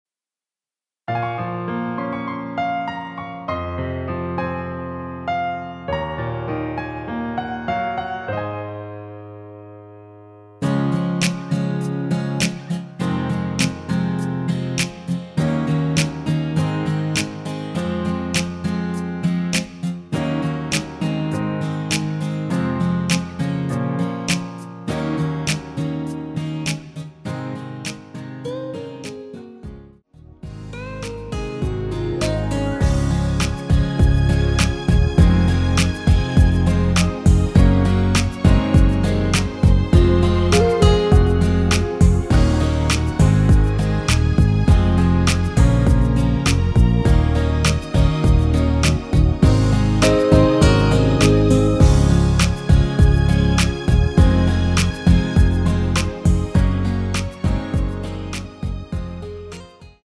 Db
앞부분30초, 뒷부분30초씩 편집해서 올려 드리고 있습니다.